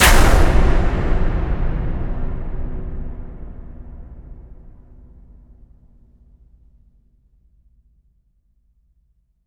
LC IMP SLAM 9A.WAV